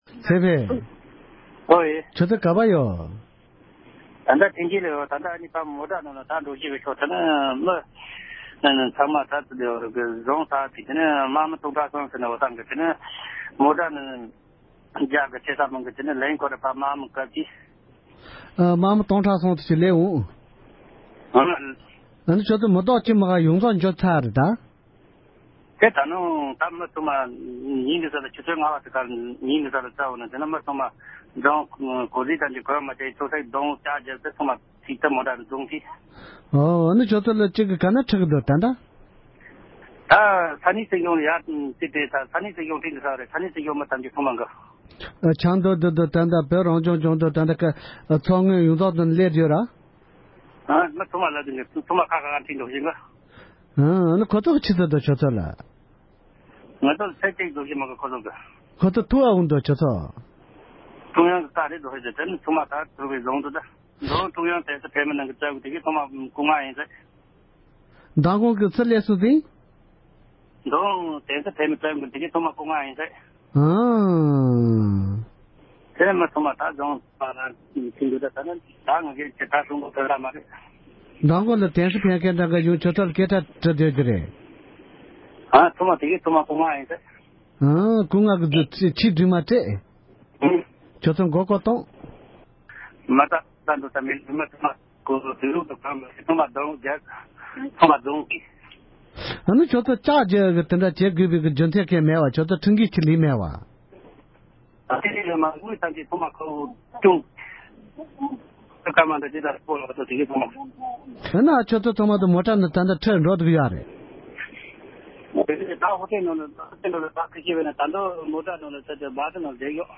སྒྲ་ལྡན་གསར་འགྱུར། སྒྲ་ཕབ་ལེན།
གྲོང་ཁྱེར་ཐེན་ཅིང་དུ་ངོ་རྒོལ་ནང་མཉམ་ཞུགས་གནང་མཁན་གྱི་བོད་མི་ཞིག